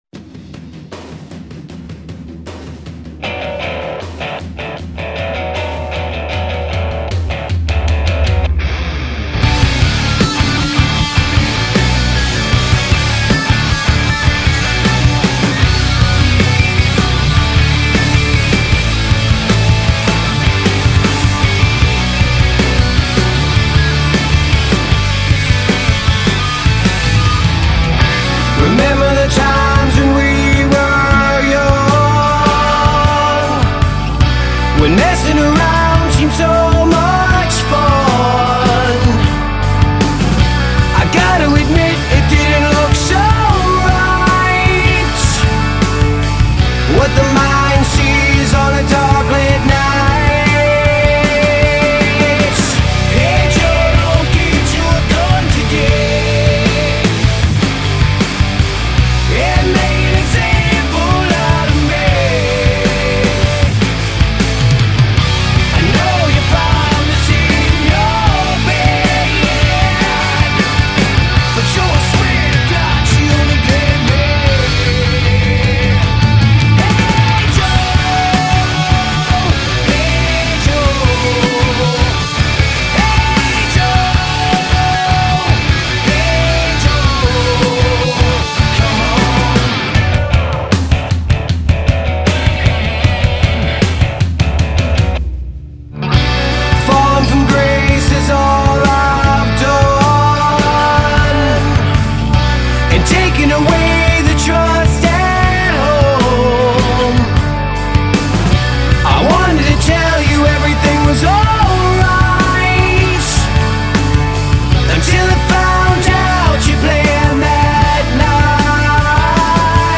Heavy metal
Rock & Roll
Prog rock